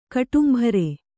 ♪ kaṭumbhare